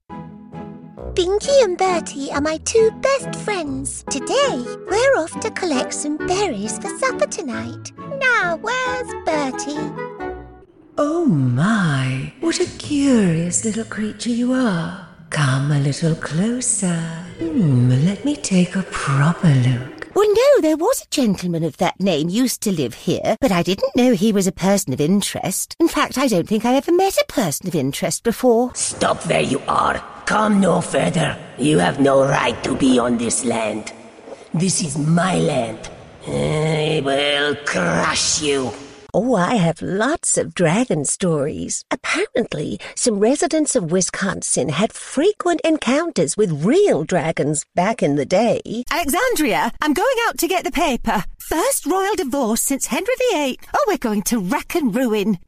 Inglés (Británico)
Cálida, Amable, Natural, Profundo, Maduro